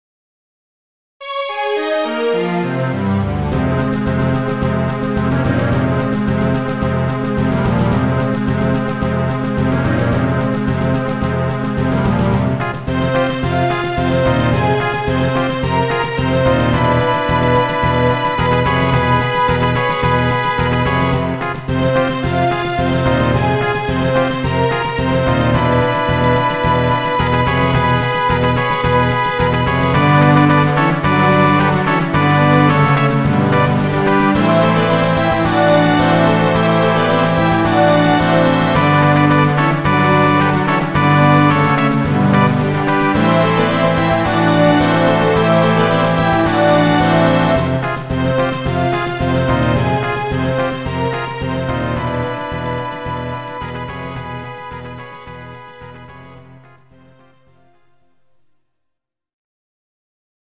GS音源。